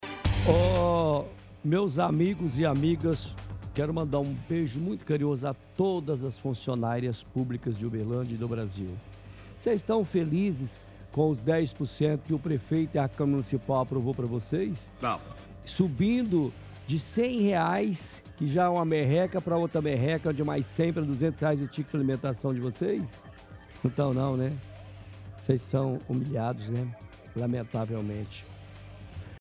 Próprio radialista já responde e diz que não estão.